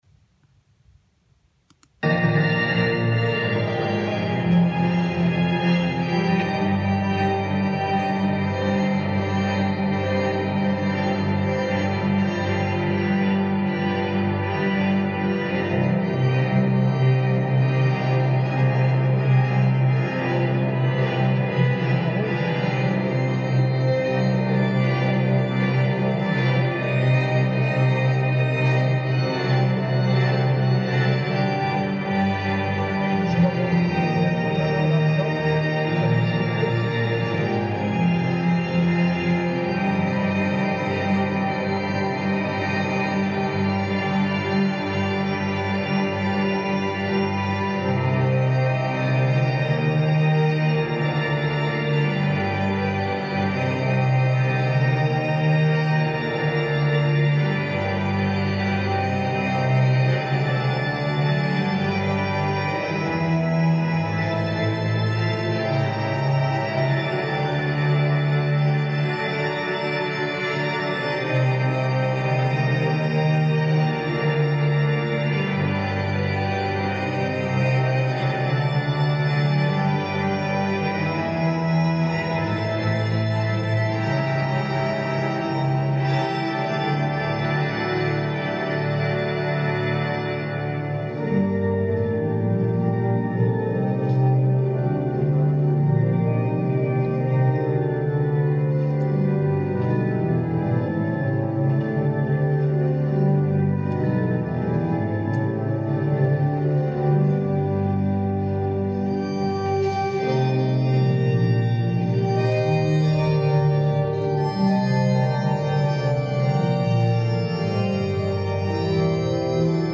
Grand Orgue Cavaillé-Coll de Saint-Saëns
aux Grandes Orgues, écouter les organistes,
Improvisation